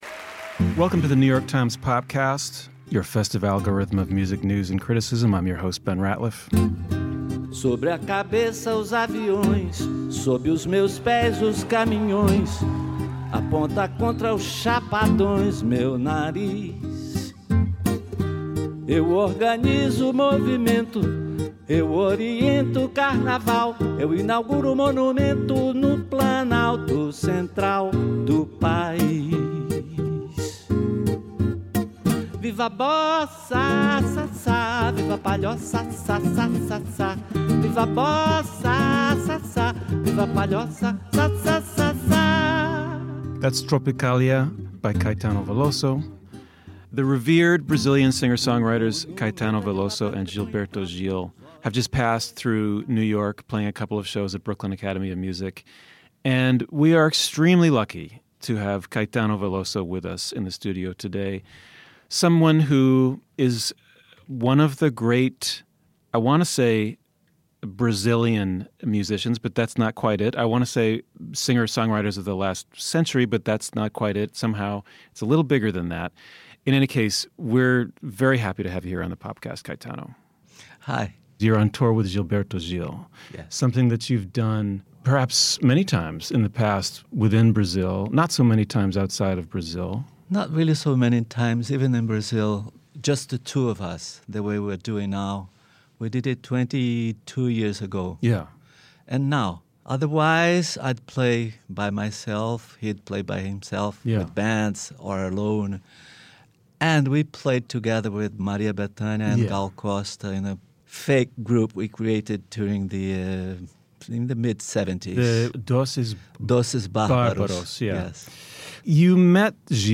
Mr. Veloso, the great Brazilian singer and songwriter, discusses current events in his country.